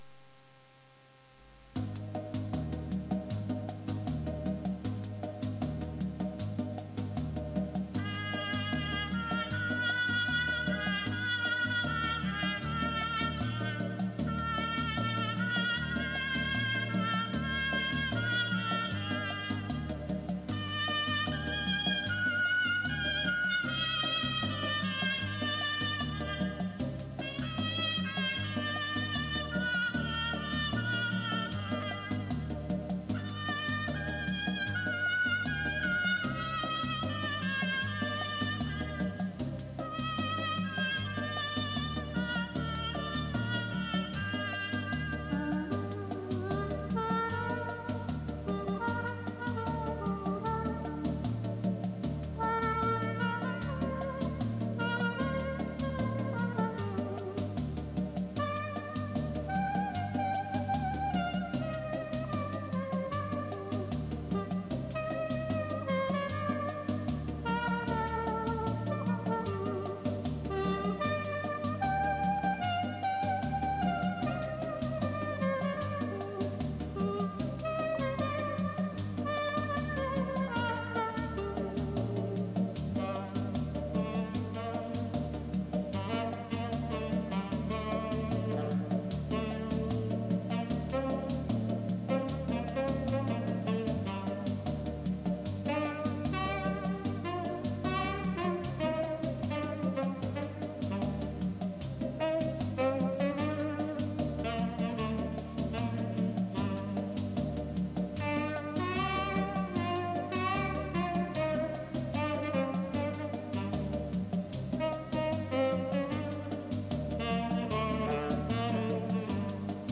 抒情的舞曲音乐，谢谢分享！